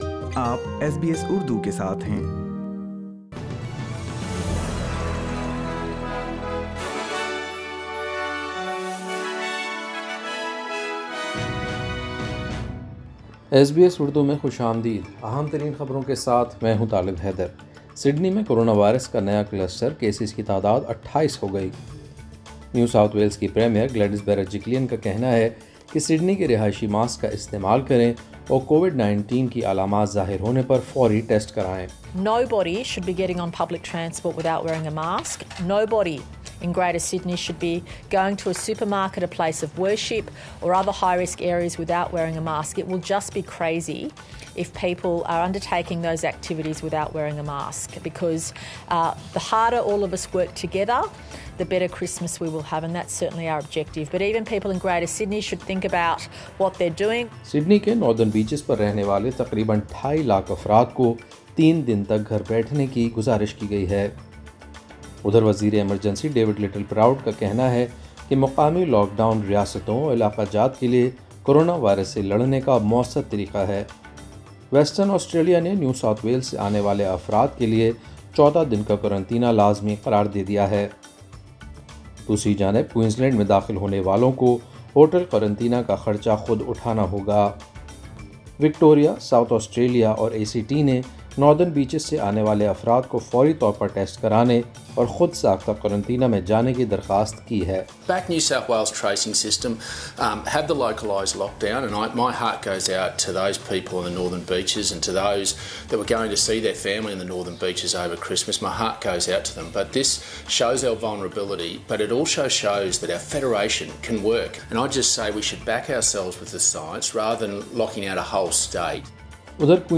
ایس بی ایس اردو خبریں 18 دسمبر 2020